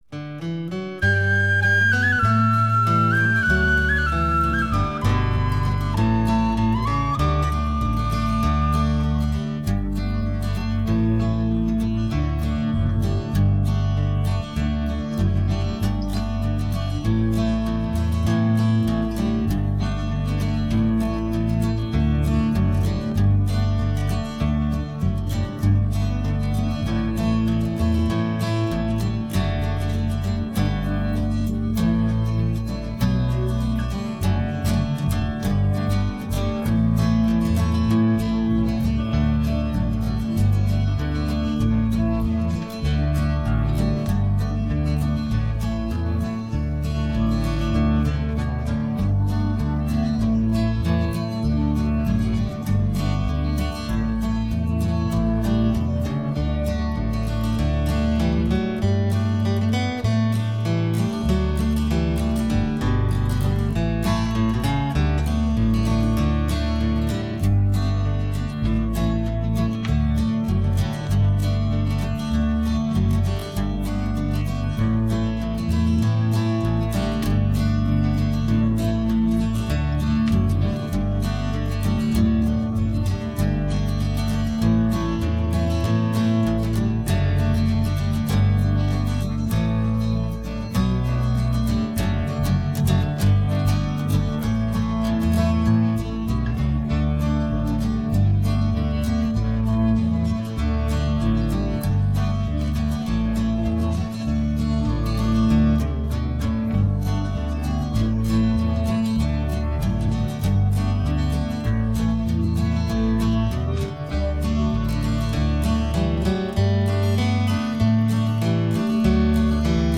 Runterladen (Mit rechter Maustaste anklicken, Menübefehl auswählen)   Harvst (Playback)
Harvst__4_Playback.mp3